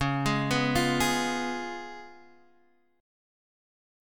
C#7 chord